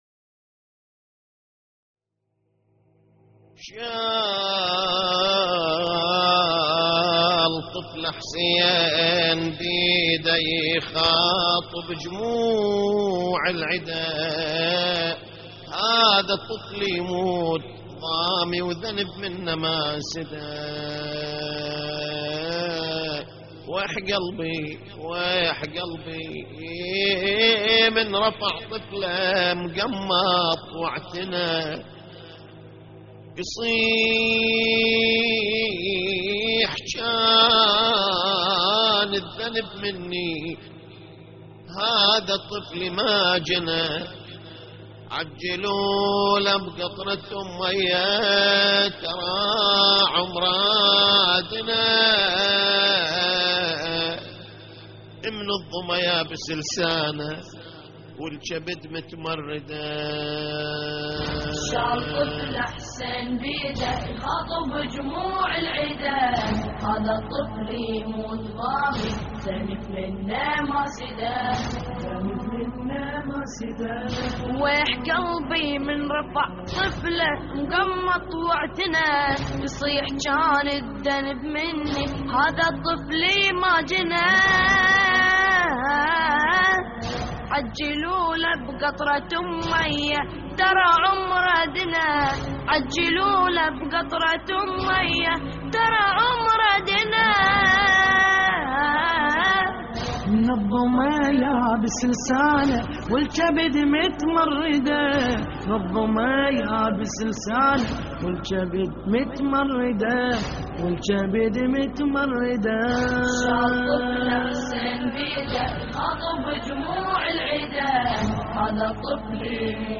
اللطميات الحسينية
موقع يا حسين : اللطميات الحسينية شال طفله حسين بيده يخاطب جموع العداء - استديو «يتيمة» لحفظ الملف في مجلد خاص اضغط بالزر الأيمن هنا ثم اختر (حفظ الهدف باسم - Save Target As) واختر المكان المناسب